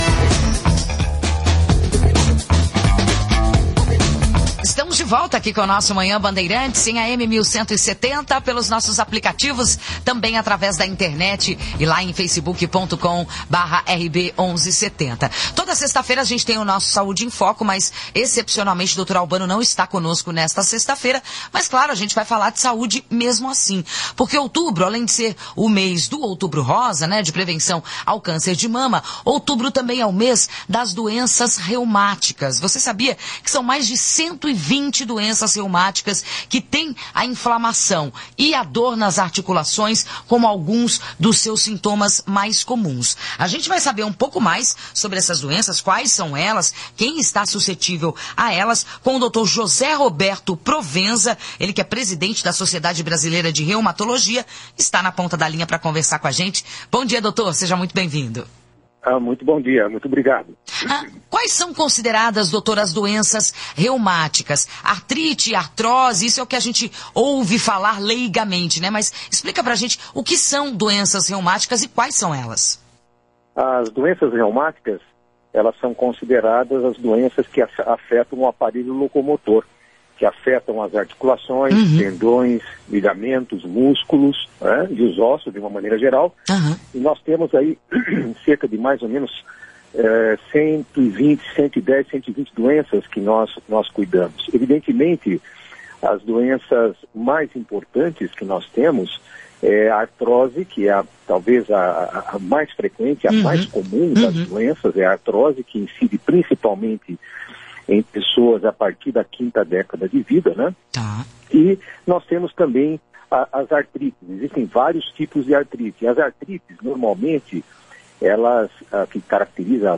Em entrevista de 12 minutos para Rádio Bandeirantes AM (Campinas)